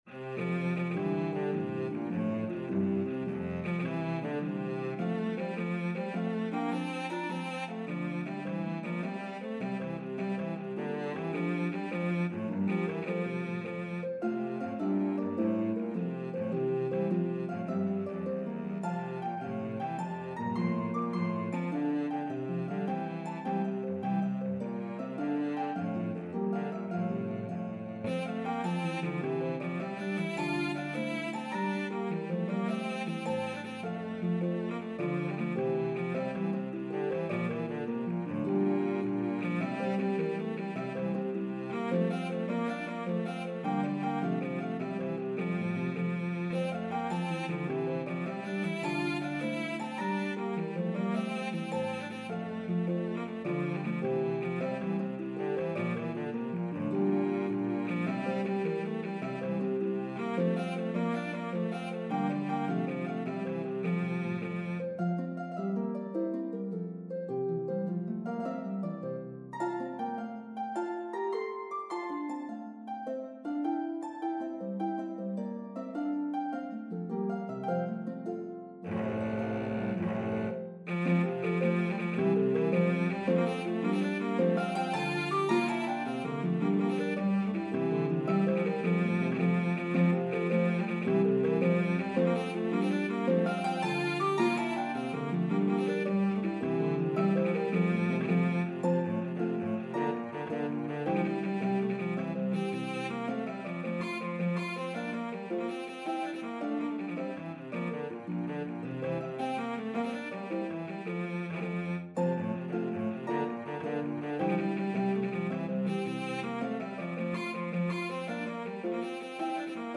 The medley progresses through 3 keys.